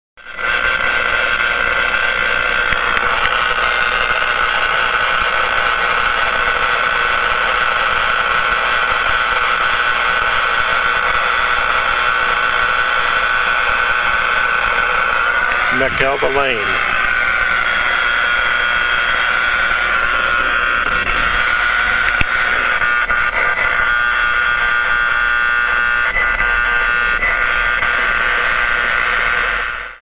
The files in this section are recordings of RF noise from electric utilities, BPL and industrial equipment.
Noise Source: Powerline noise.
Where Found: 2 metre band, AM.
Notes: Powerline noise recorded on the 2 metre band in AM mode while mobile.